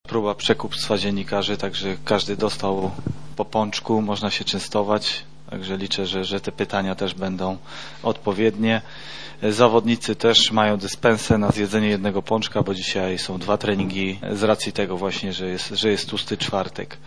Trener Piotr Stokowiec przybył na konferencję z pudełkiem pączków. Częstując dziennikarzy przyznał, że to pierwszy w jego karierze przypadek korupcji.